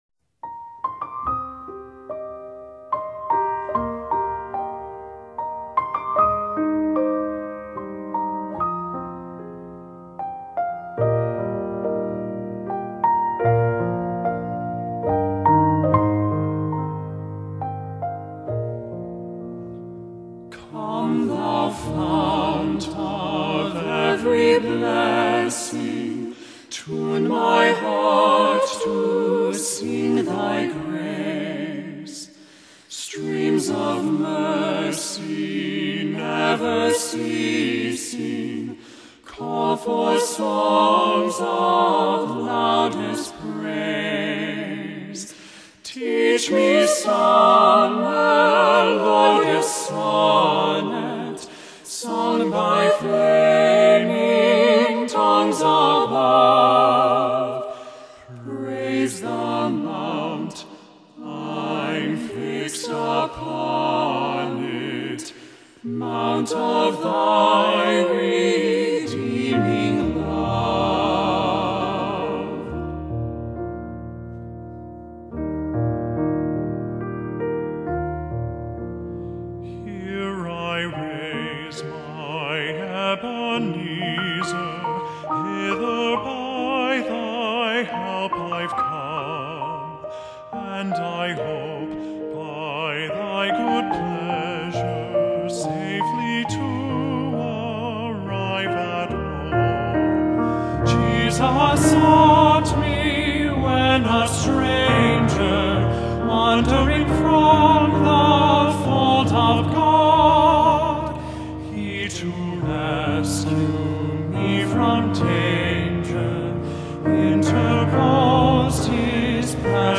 SATB Choir and Piano
Hymn arrangement